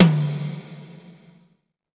TOM XTOMH0MR.wav